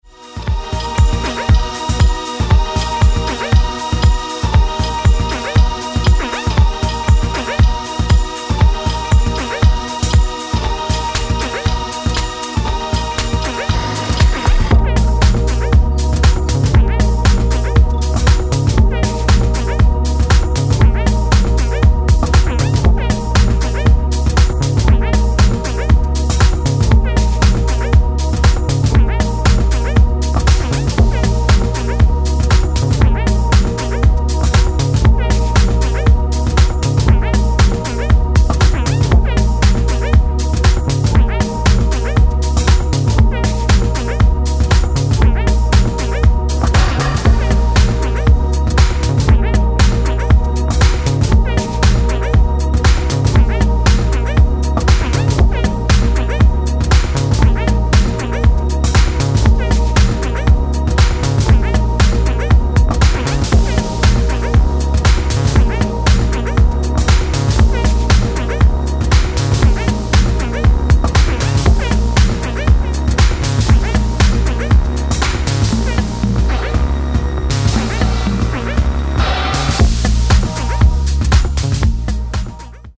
House